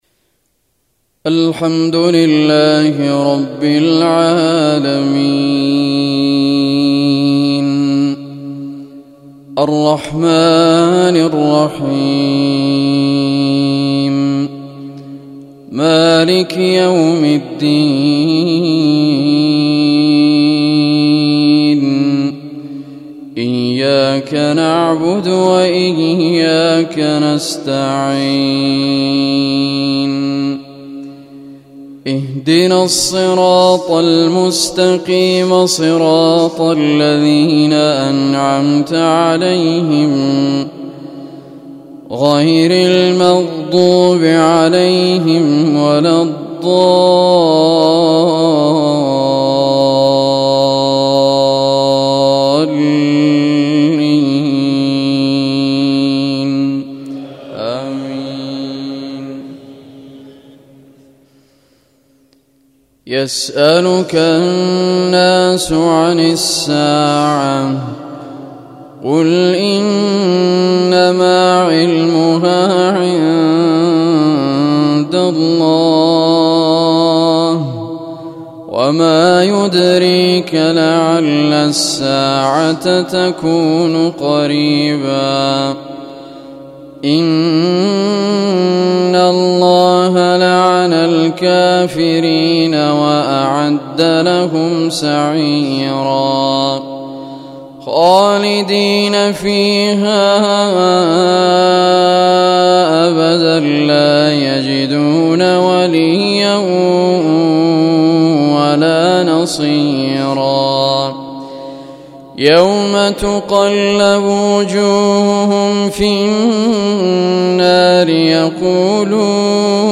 WevFro: تلاوة مميزة من سورتي الأحزاب والمنافقون بصوت القارئ رعد الكردي
أستماع مباشر 🎧- تحميل مباشر 📥- بصيغة MP3 - 🎙 القرآن الكريم برواية حفص عن عاصم بصوت الشيخ رعد الكردي 🎙
🌟تلاوة مميزة من سورتي الأحزاب والمنافقون بصوت القارئ رعد الكردي 🌟 في هذه المقالة، نتعرّف على تلاوة مميزة من سورتي الأحزاب والمنافقون بصوت القارئ رعد الكردي ، والتي تتميز بصوت جميل ، وترفع القلوب وتنسي الدنيا وأهوالها. ويأتينا هذا الأداء بأسلوب شيق ومؤثر